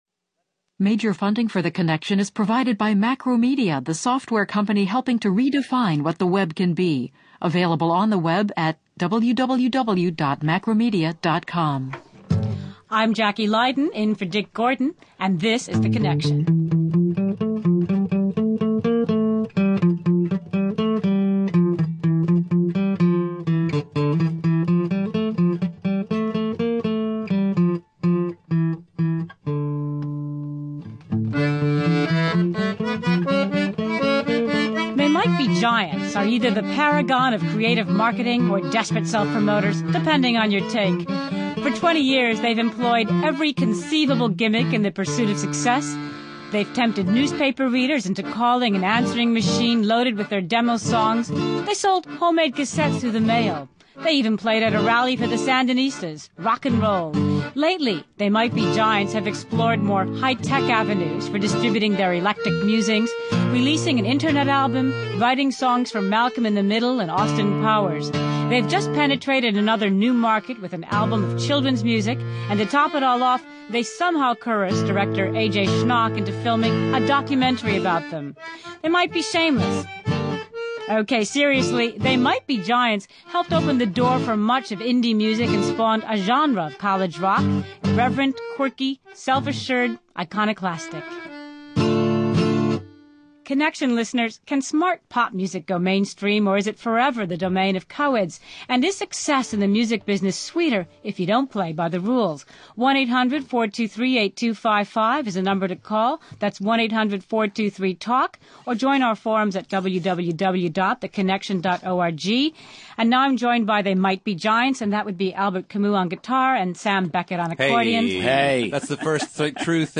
Vetting the verbiage and exploring the sheer quirkiness of They Might Be Giants. Guests: John Flansburgh and John Linnell of “They Might Be Giants”